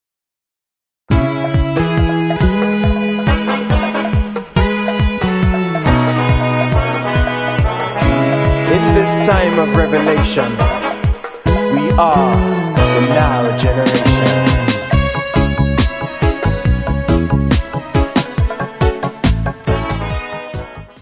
: stereo; 12 cm
Área:  Pop / Rock